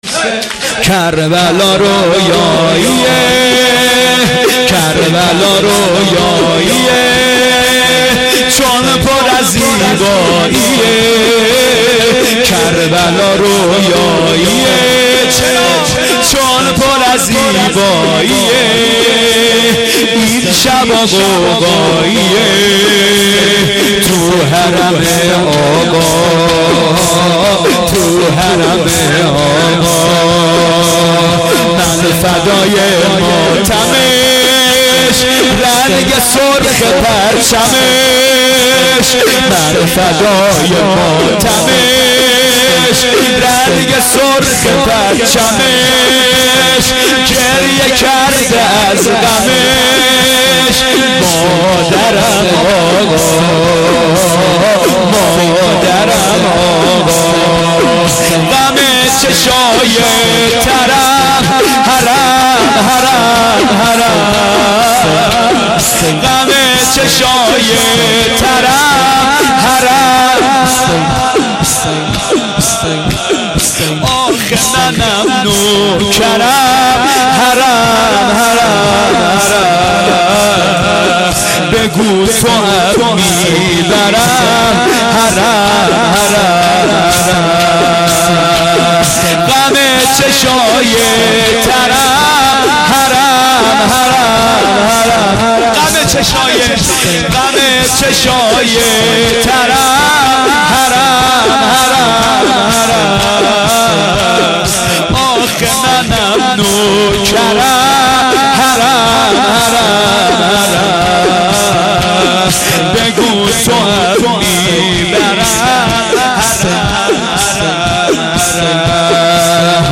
هیئت عاشورا-قم
شهادت امام جواد ع-18مرداد97